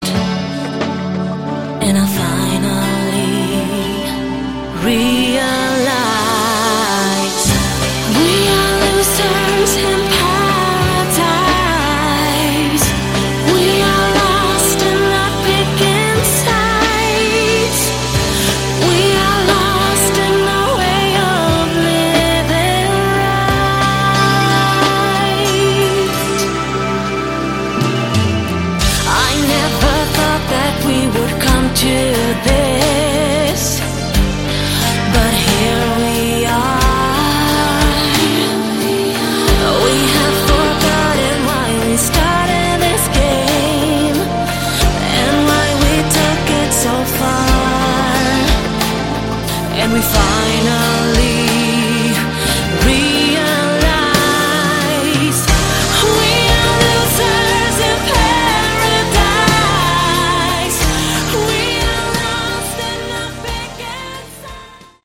Category: Melodic Rock
lead and backing vocals
guitars
drums, keyboards
bass